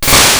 effect_ember.wav